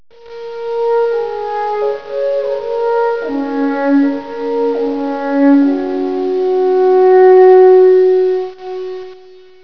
Impression of Frutigen in Bern Switzerland
alphorn (click to hear)
alphorn.wav